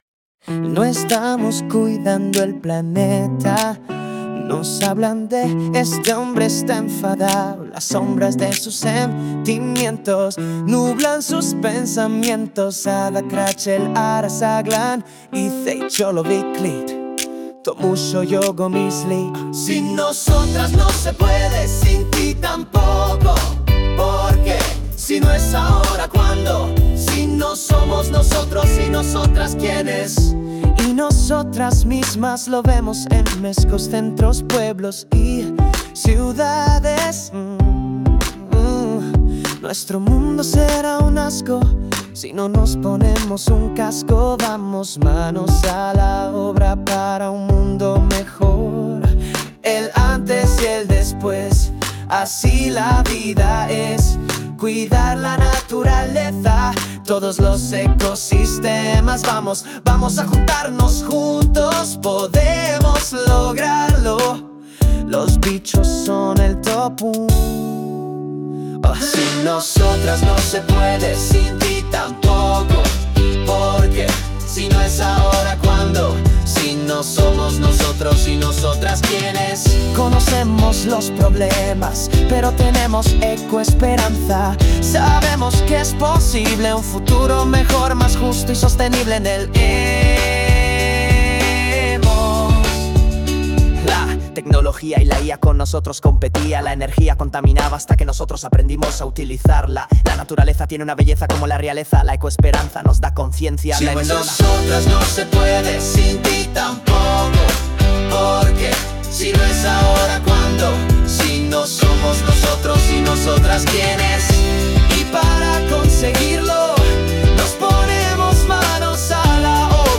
canción con los mensajes recogidos sobre el cuidado del planeta y con ayuda de la IA